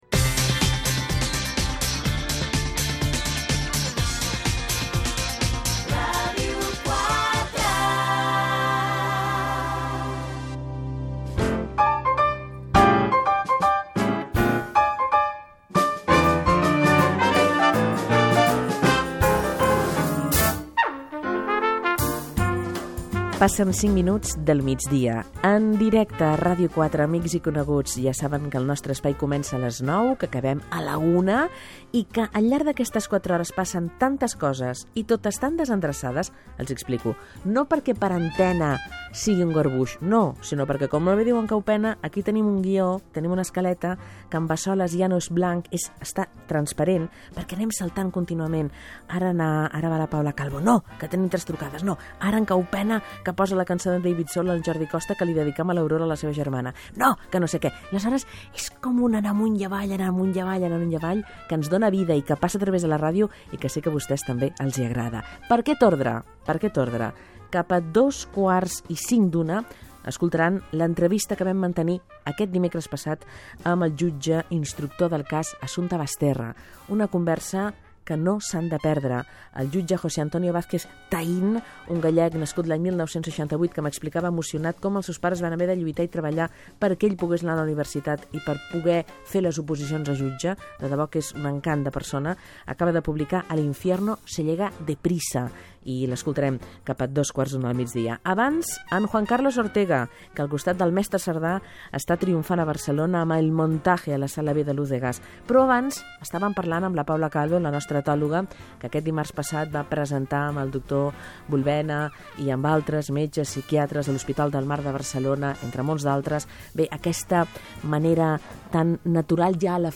entrevista a Juan Carlos Ortega i Xavier Sardà, que presenten l'obra de teatre “El montaje” Gènere radiofònic Entreteniment